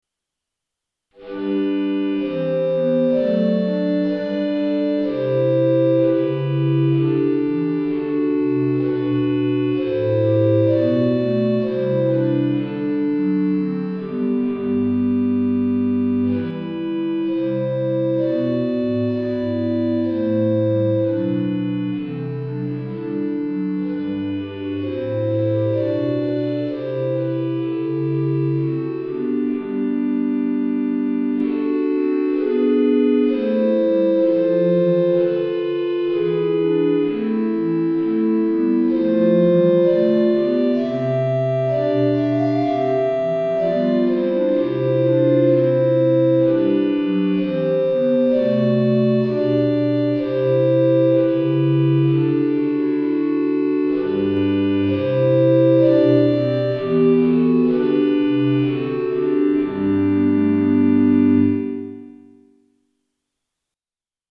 Sample Sound for Practice 練習用参考音源：MIDI⇒MP3　Version A.17
Tonality：G (♯)　Tempo：Quarter note = 64
1　 Recorder　強弱付